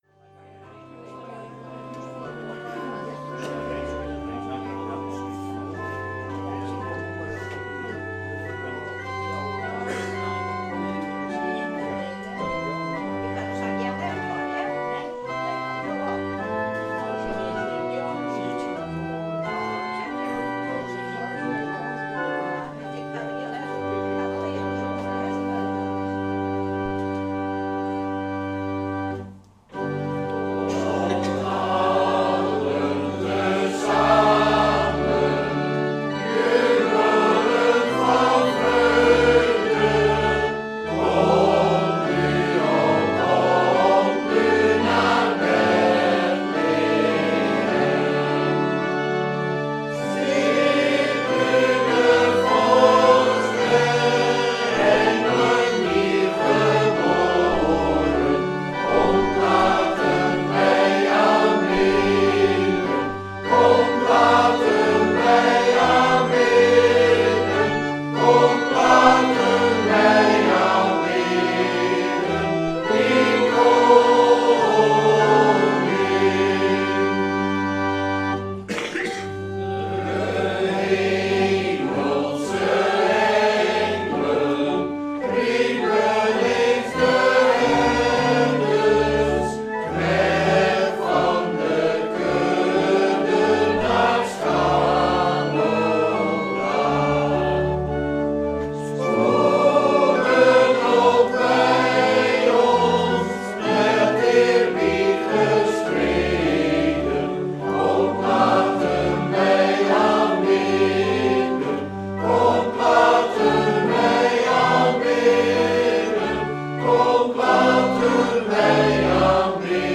2e Kerstdag
Categorie: Mattheus Label: Schriftlezing: Matheus 1 vs 18-25